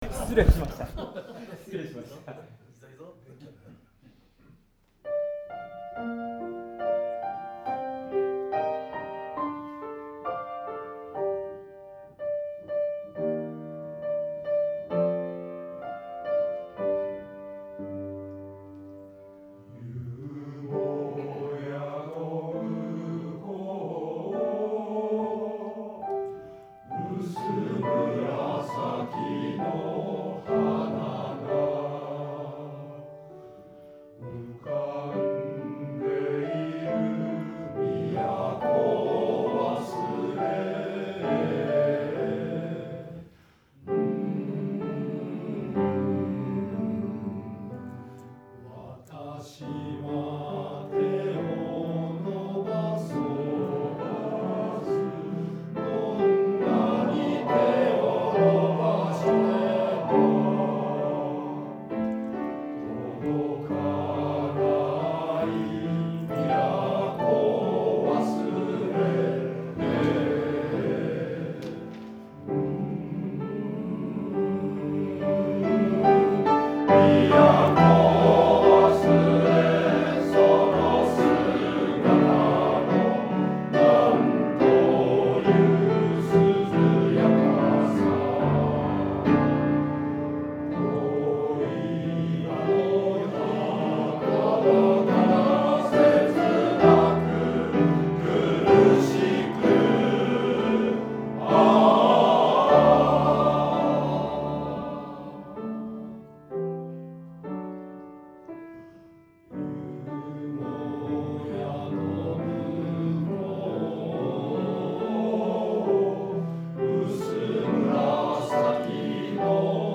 最後に、合唱祭の本番と同じ立ち位置で、２曲通して歌いました。
結果、これまでより少し小さくなりましたが、その代わりハモリ始めました。
タイミングが合ってない、などが散見されます。
・全体に遅れ気味、特に出だしの遅れは目立ちます。
口を閉じている声がします。
練習録音　　　⇒